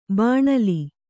♪ bāṇali